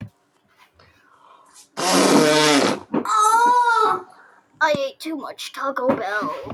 Toco Bell Farts Be Like Sound Button - Free Download & Play